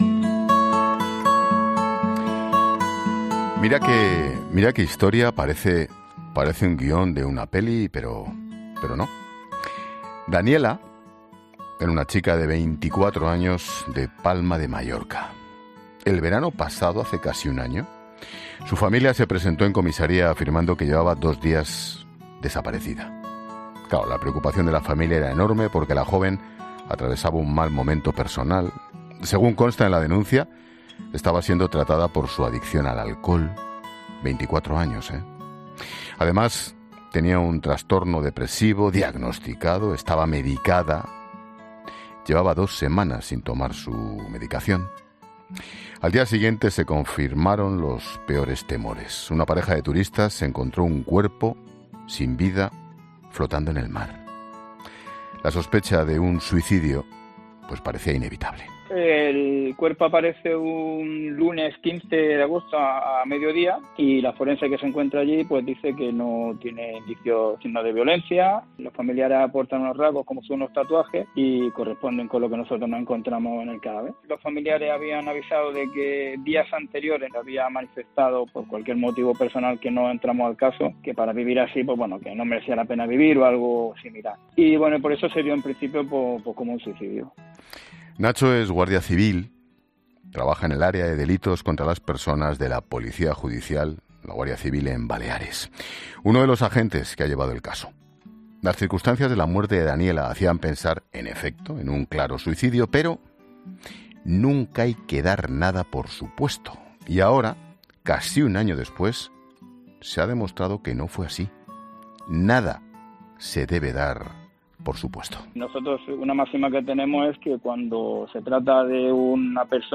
Un agente de la Guardia Civil que ha llevado el caso explica en La Linterna qué vieron al repasar las cámaras de seguridad